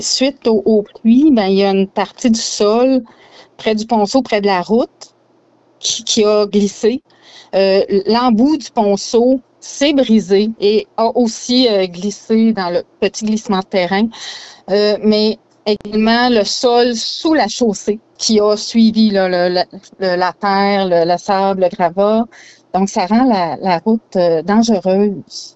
La mairesse, Sandra St-Amour, est revenue sur les circonstances de cet affaissement qui cause un détour dans ce secteur.